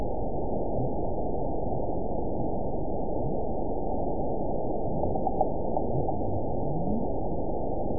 event 912628 date 03/30/22 time 13:29:58 GMT (3 years, 1 month ago) score 9.04 location TSS-AB05 detected by nrw target species NRW annotations +NRW Spectrogram: Frequency (kHz) vs. Time (s) audio not available .wav